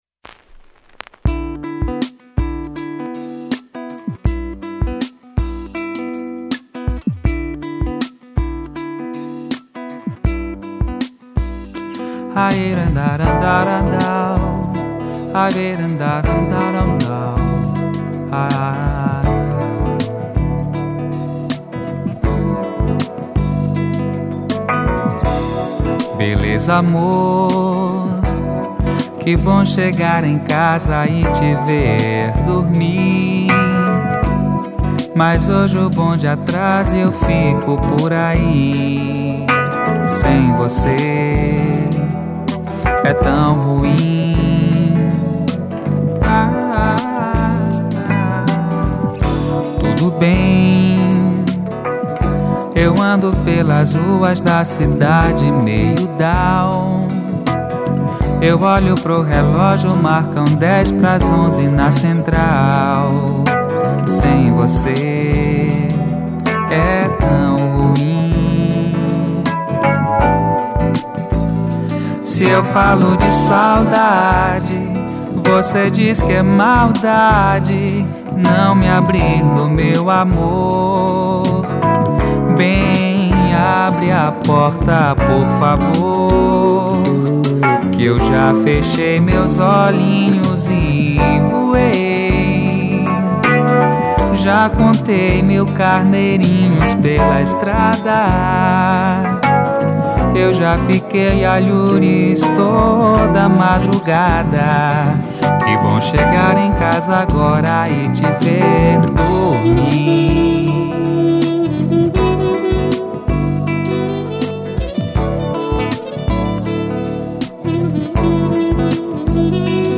835   06:48:00   Faixa:     Mpb
Clarinete, Saxofone Tenor
Voz, Violao Acústico 6